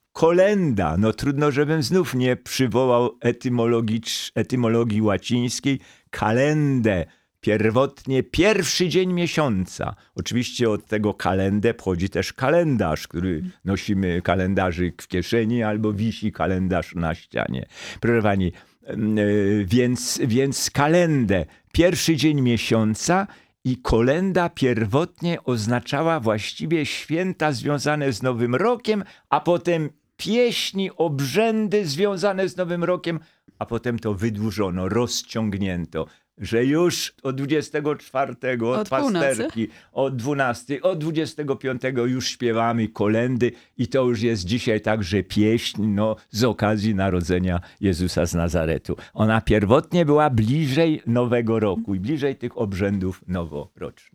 Językoznawca, prof. Jan Miodek odwiedził w pierwszy dzień świąt studio Radia Rodzina.